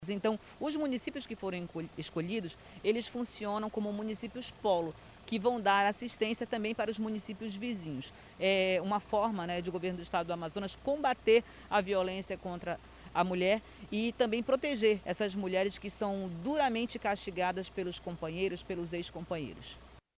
Sonora-Mirtes-Salles-.mp3